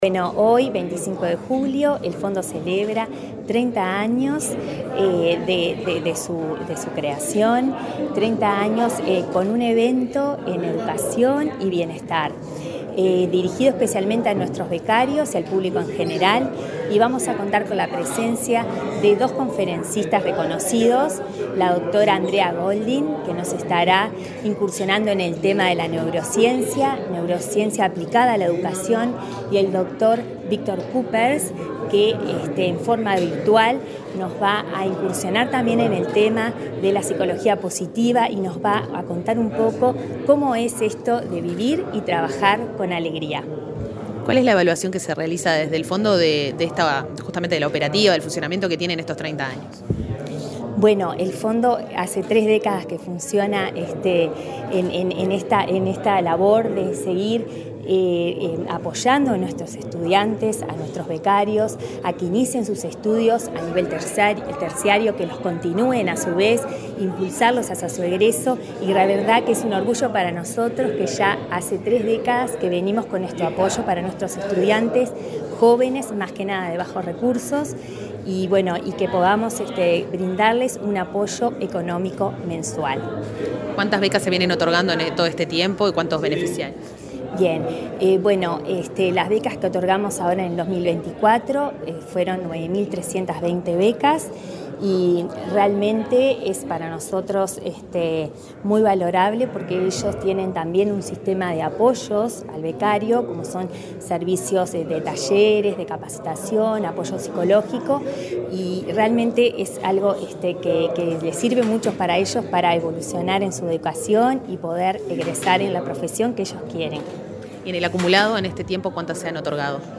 Entrevista a la presidenta del Fondo de Solidaridad, Rosario Cerviño